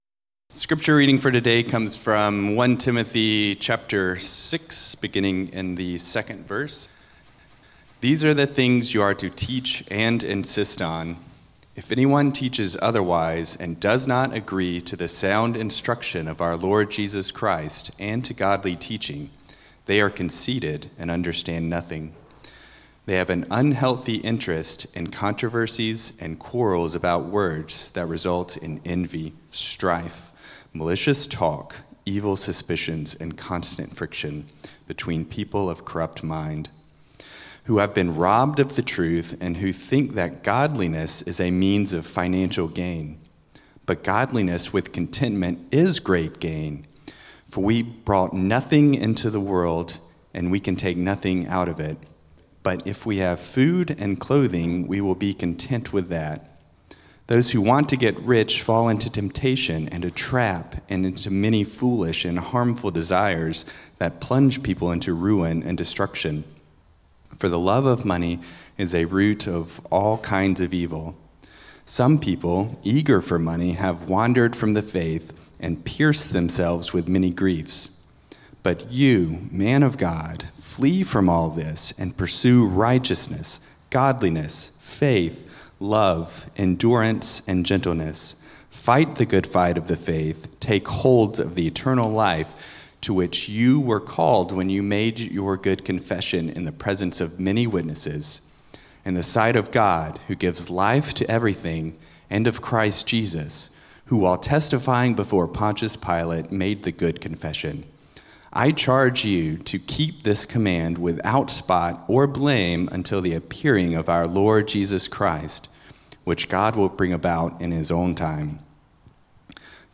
1 Timothy Sermon outline